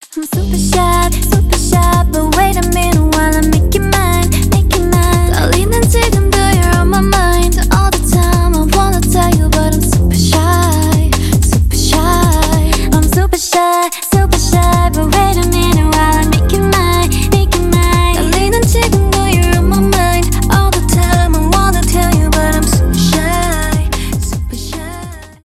jersey club
k-pop